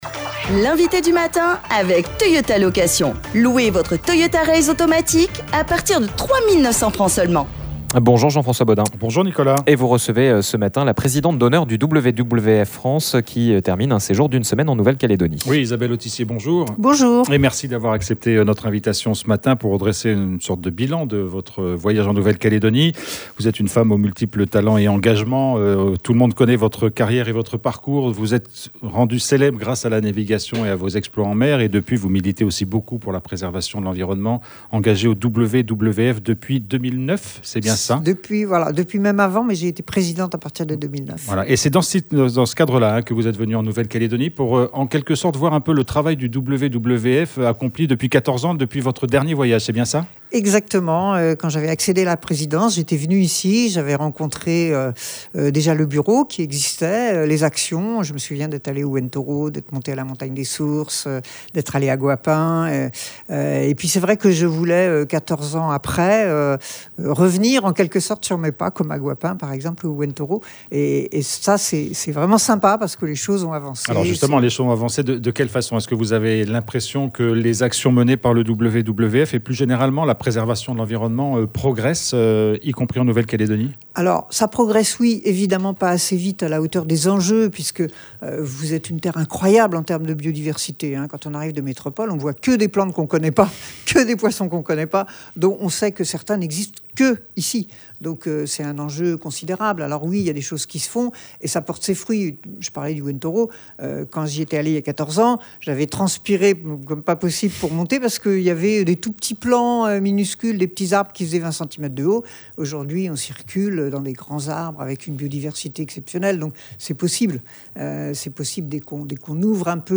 Isabelle Autissier achève une visite d’une semaine en Calédonie. Elle est notre invitée du matin.